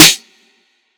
Rodeo SNaRe.wav